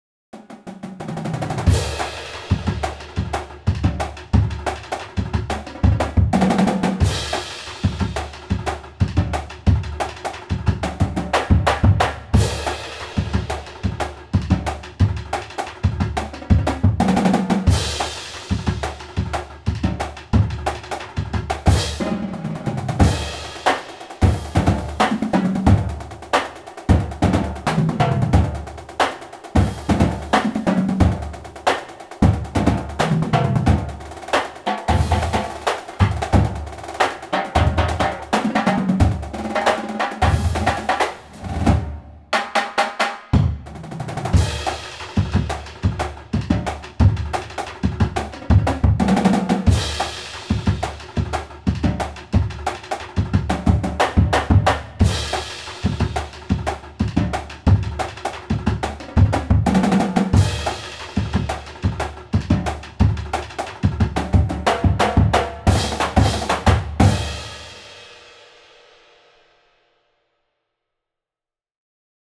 Voicing: Drumline Feature